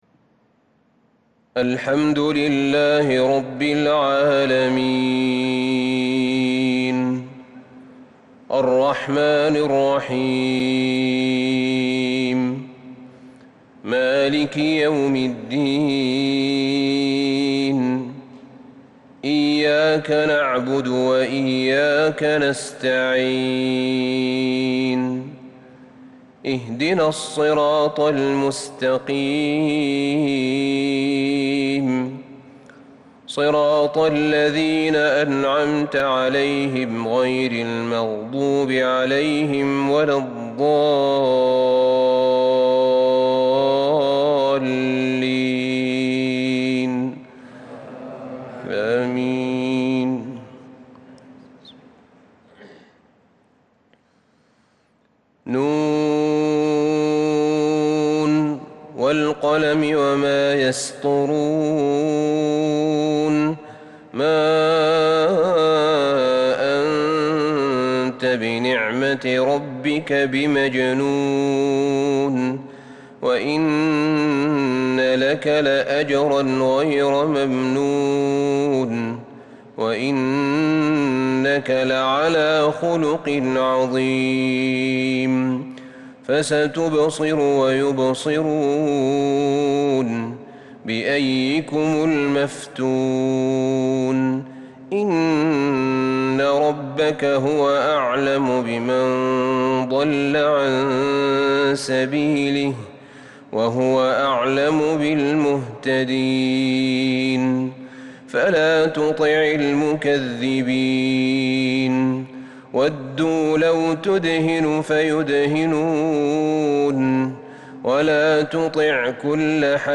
فجر الاثنين 26 شوال 1442هــ سورة القلم | Fajr prayer from Surah Al-Qalam 7-6-2021 > 1442 🕌 > الفروض - تلاوات الحرمين